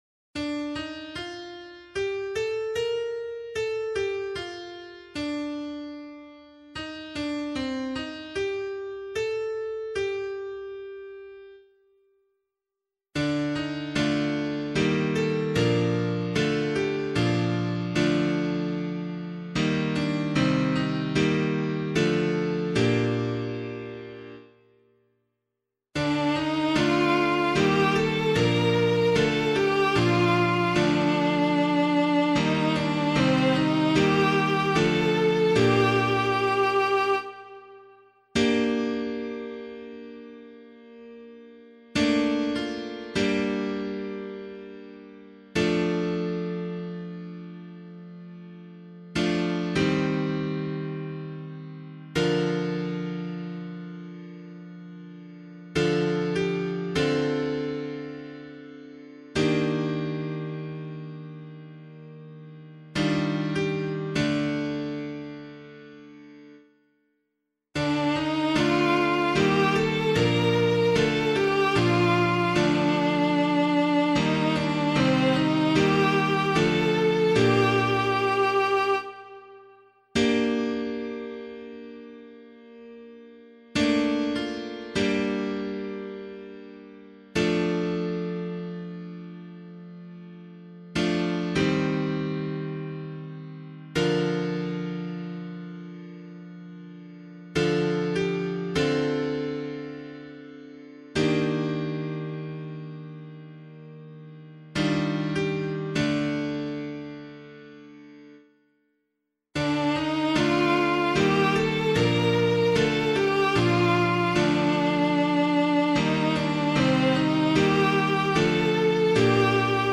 014 Lent 2 Psalm A [APC - LiturgyShare + Meinrad 4] - piano.mp3